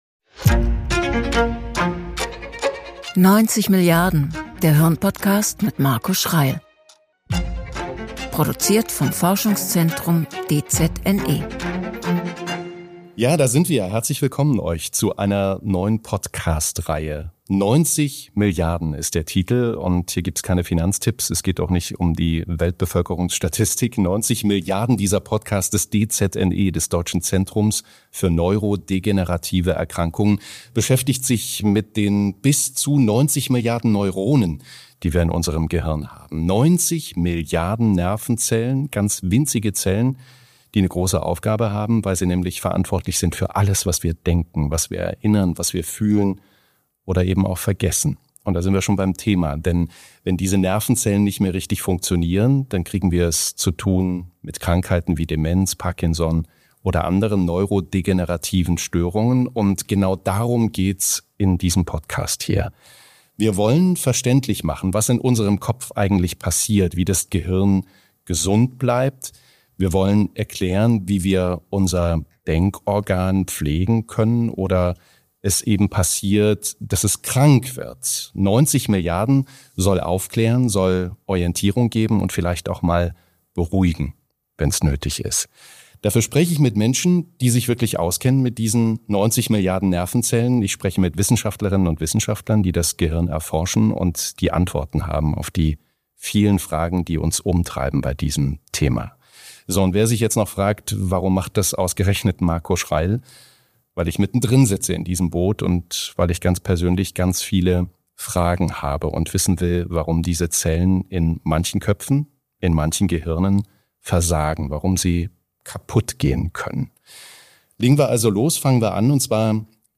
Wissenschaft auf die Ohren Die Geheimnisse der Neuronen (DZNE, 90 Milliarden) Play episode September 23 40 mins Bookmarks View Transcript Episode Description Moderator Marco Schreyl spricht im Podcast 90 Milliarden mit Fachleuten aus Wissenschaft und Praxis – und mit Menschen, die unmittelbar oder indirekt von Gehirnerkrankungen betroffen sind.